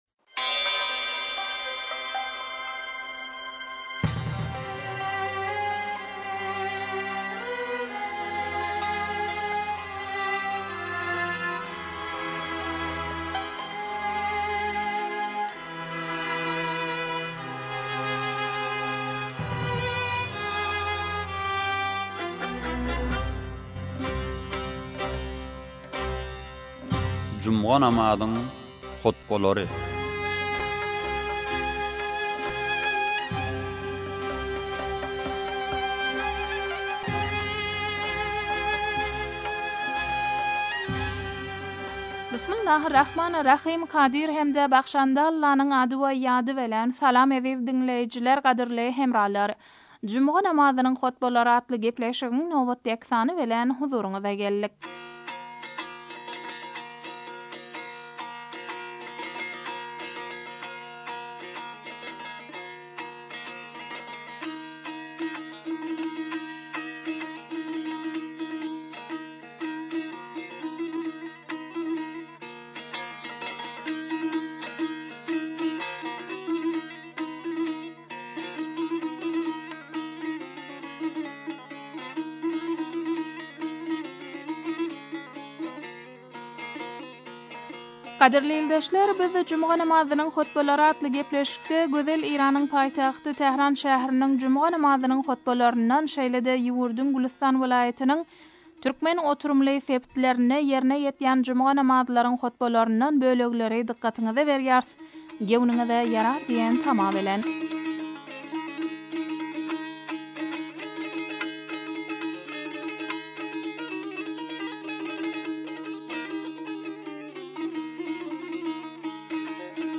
juma namazyň hutbalary